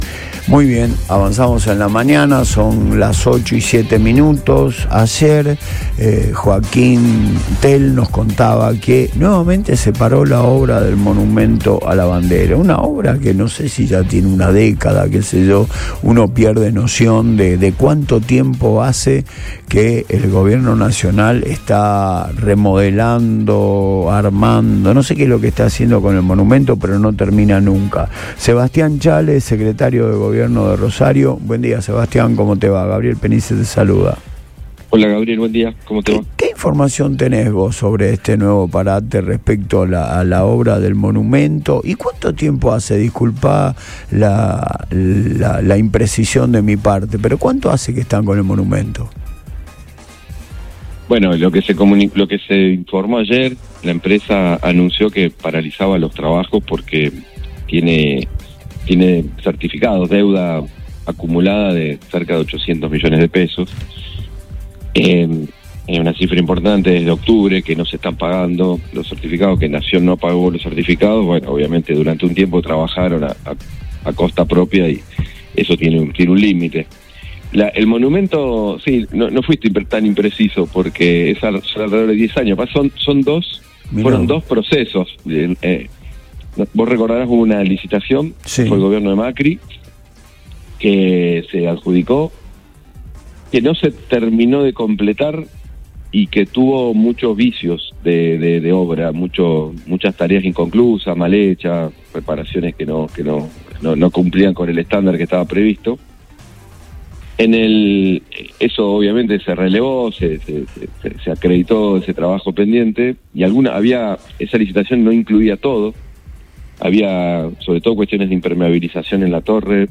Sebastián Chale, Secretario de Gobierno, confirmó en Radio Boing que los trabajos volvieron a paralizarse por una deuda acumulada con la empresa contratista.
En diálogo en Antes de Todo, el funcionario detalló que la empresa constructora detuvo los trabajos por la falta de pago de certificados que rondan los 800 millones de pesos.